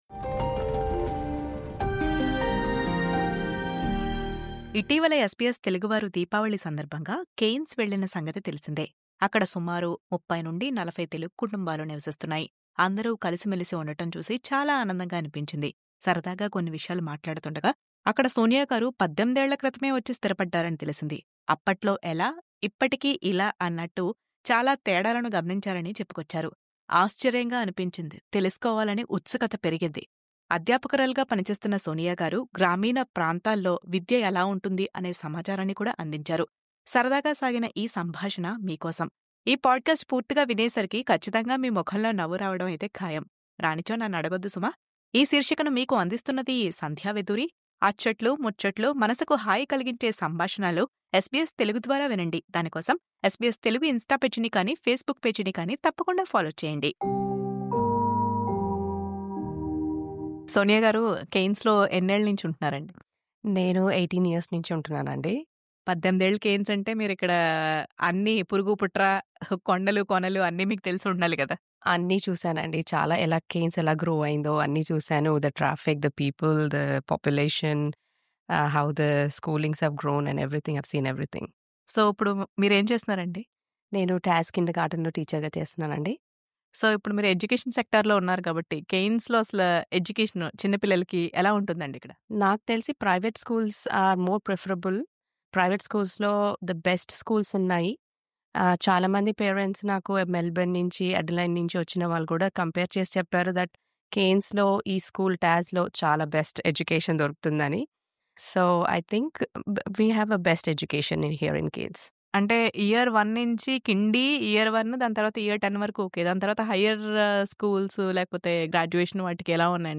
ఇటీవల SBS తెలుగు దీపావళి కోసం ప్రత్యేకంగా కెయిర్న్స్ వెళ్లిన సంగతి తెలిసిందే.
ఈ సరదా సంభాషణ మీ కోసం.